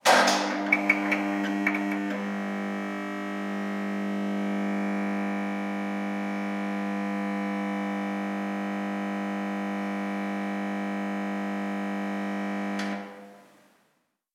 Luces fluorescentes
Sonidos: Industria
Sonidos: Hogar